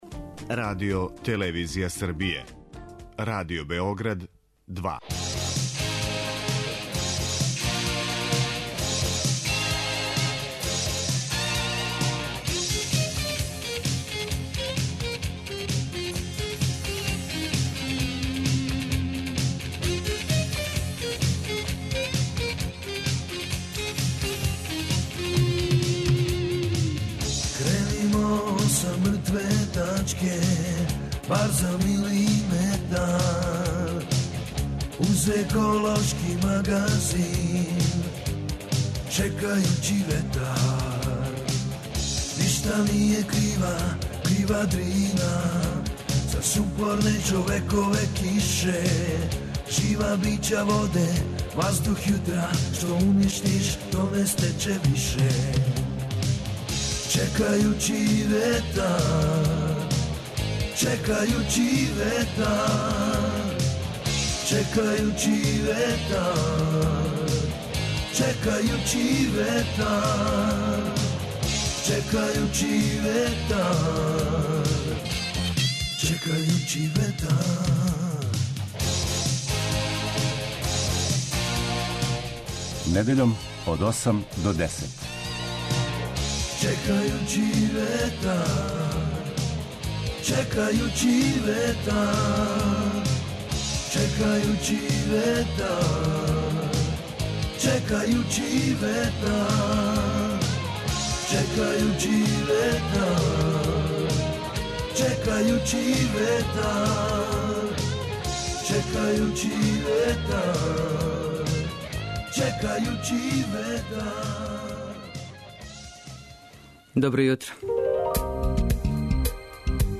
Еколошки магазин